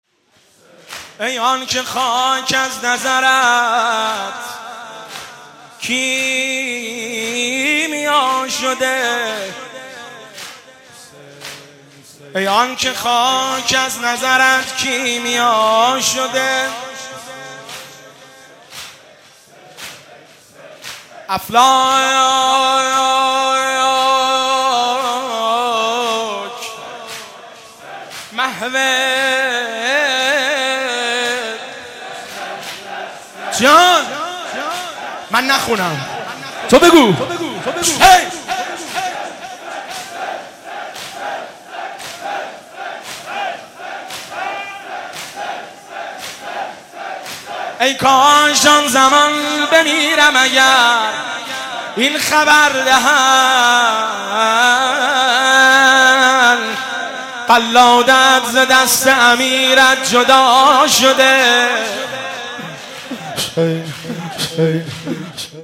مناسبت : شب بیست و دوم رمضان
قالب : زمزمه